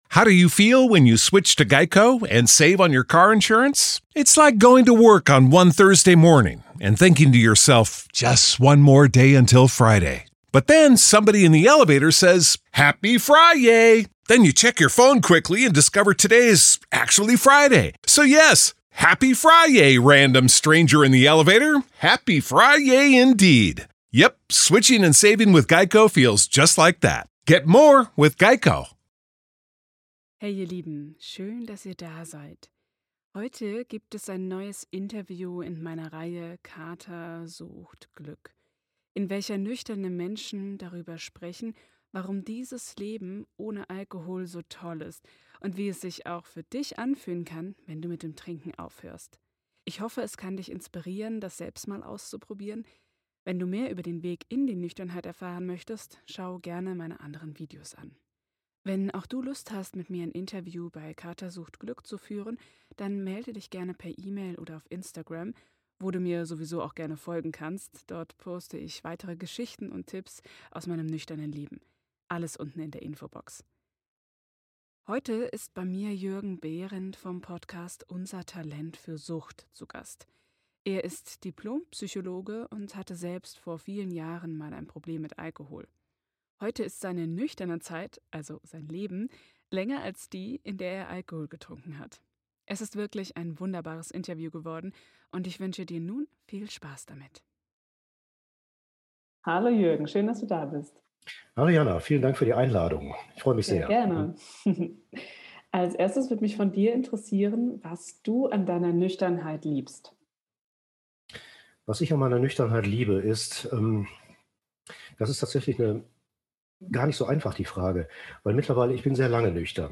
Ich hoffe, das Interview gefällt euch - schreibt mir das gerne mal in die Kommentare!